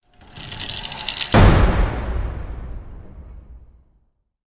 doorweightedopen.ogg